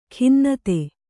♪ khinnate